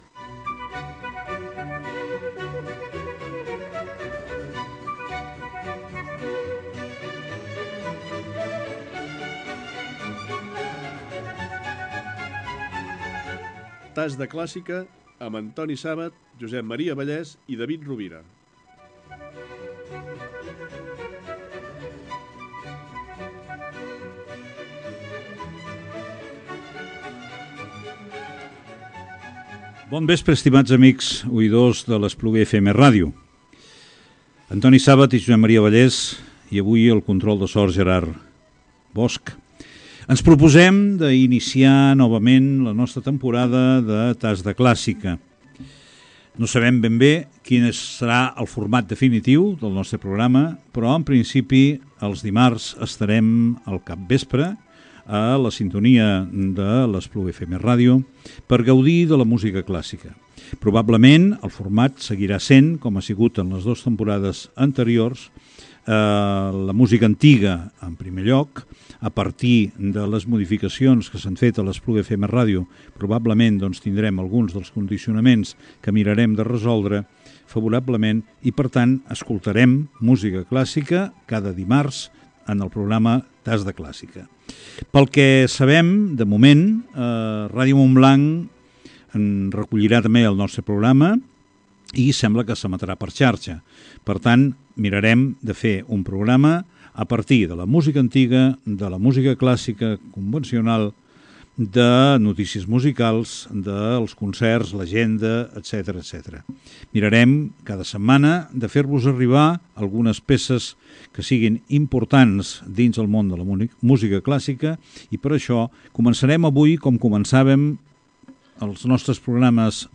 Careta del programa dedicat a la música clàssica antiga.
Musical